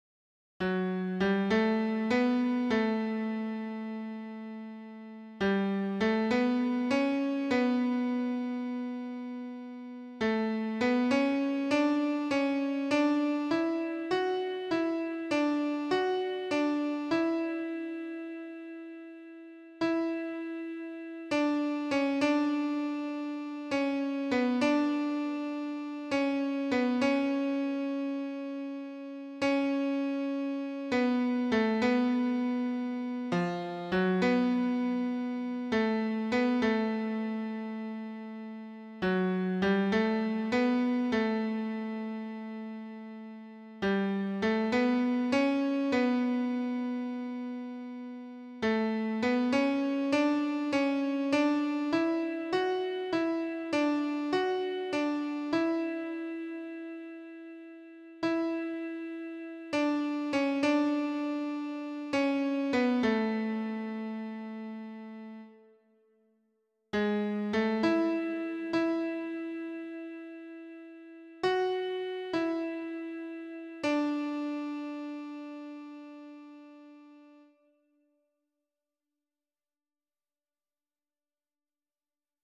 esse_seu_olhar_-_contralto[50293].mp3